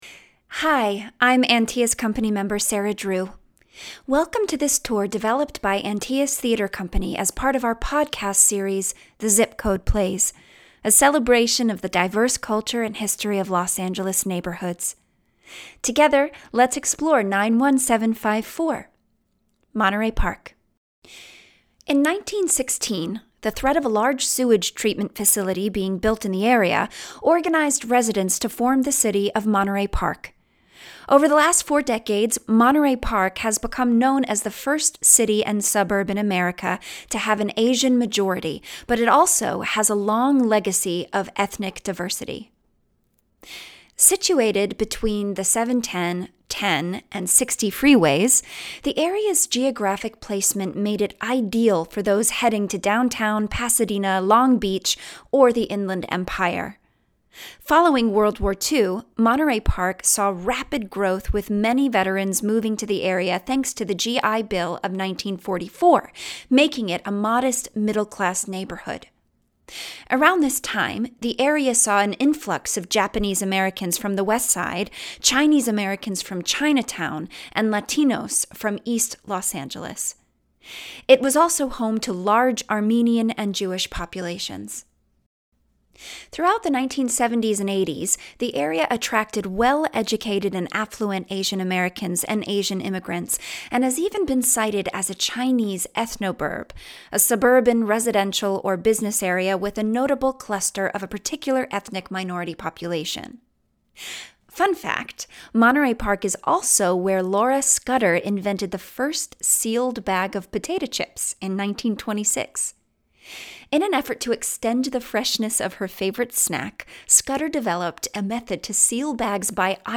This Audio Tour is Narrated by Sarah Drew